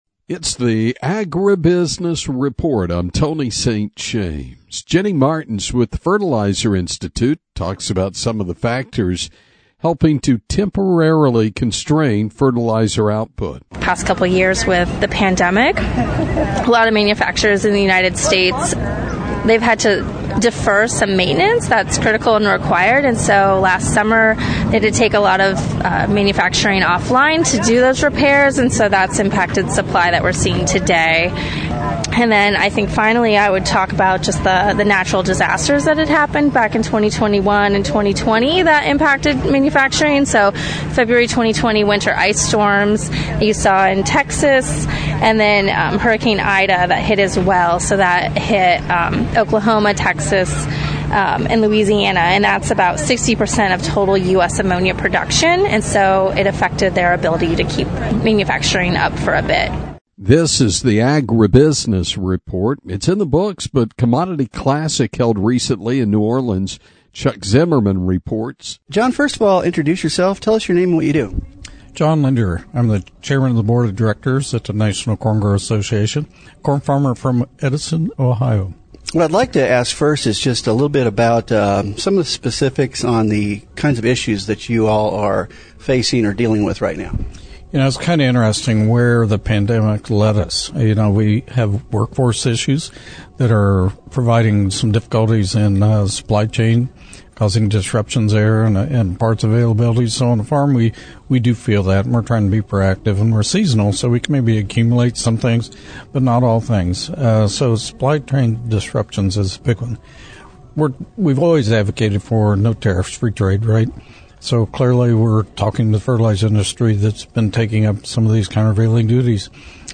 from Commodity Classic in New Orleans, LA